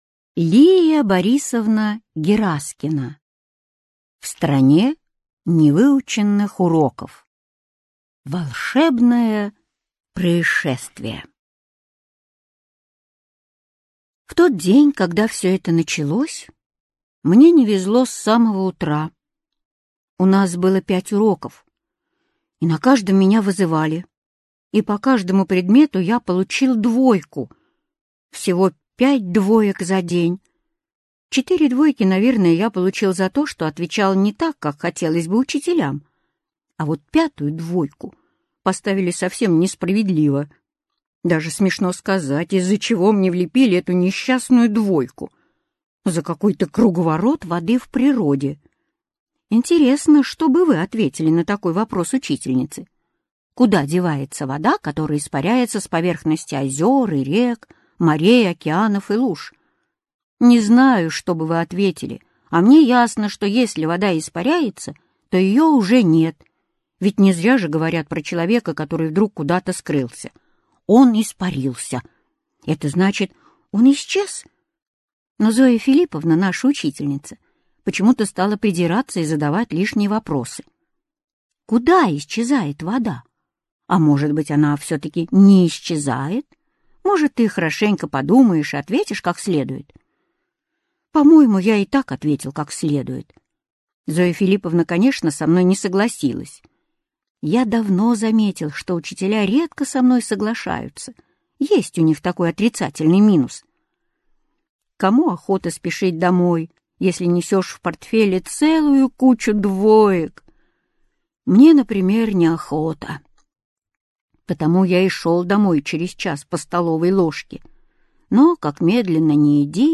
Аудиокнига В стране невыученных уроков | Библиотека аудиокниг